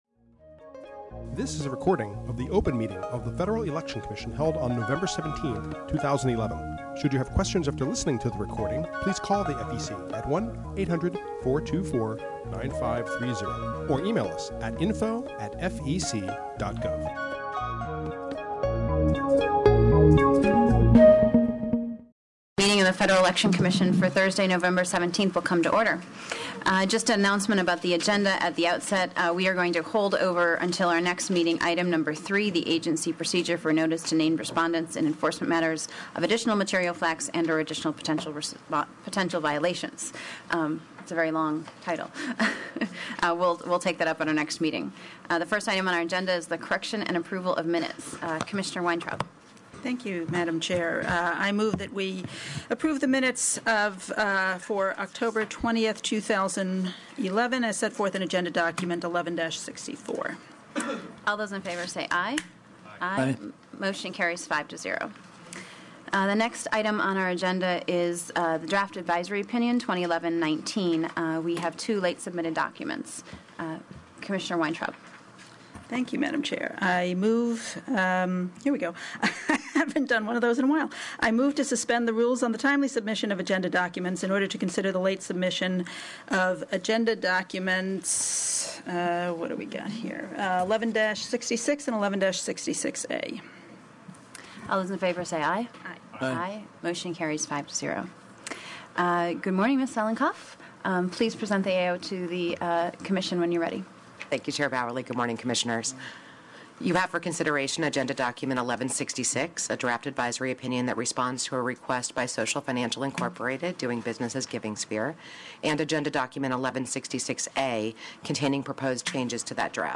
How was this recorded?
November 17, 2011 open meeting